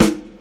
• Vinyl Full Rock Snare Sound E Key 35.wav
Royality free snare one shot tuned to the E note. Loudest frequency: 1109Hz
vinyl-full-rock-snare-sound-e-key-35-yZP.wav